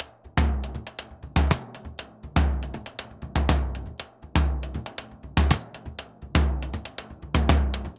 Bombo_Candombe_120_1.wav